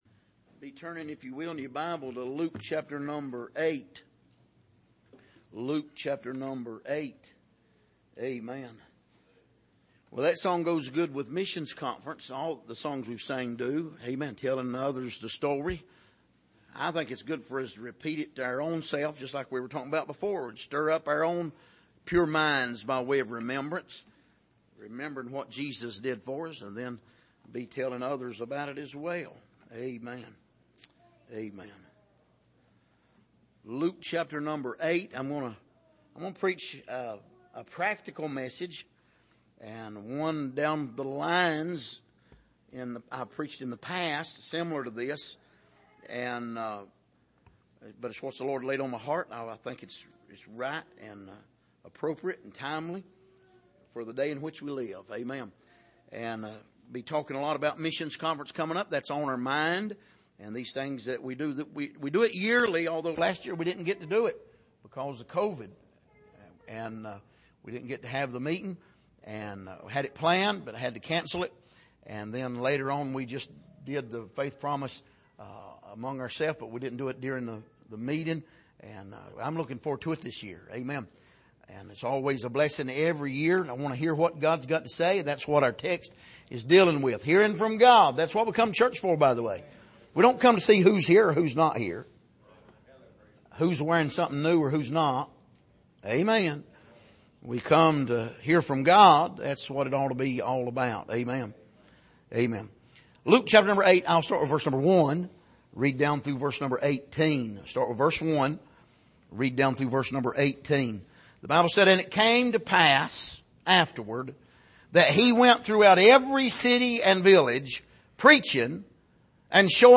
Passage: Luke 8:1-18 Service: Sunday Morning